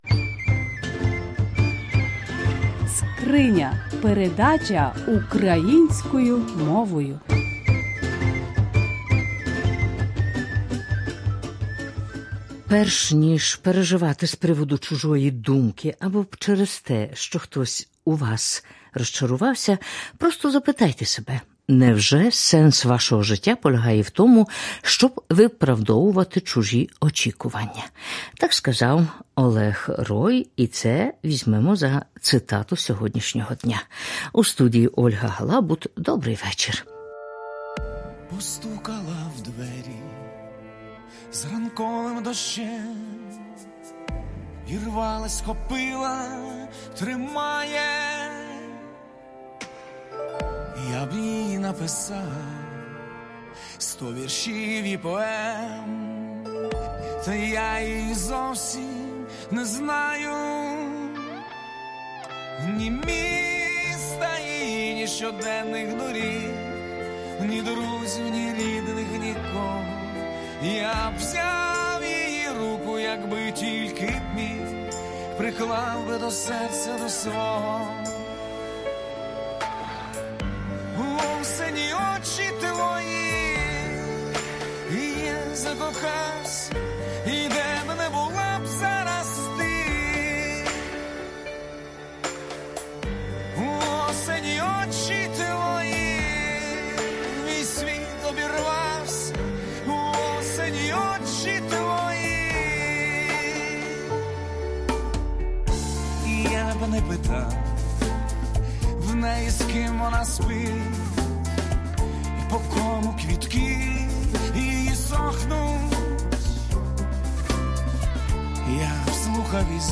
У Жешуві доми українців і відвідує вертеп. Складається він з дітей проживаючих у місті українців і українських біженців. Вертеп з Жешува йде з колядою у ваш дім.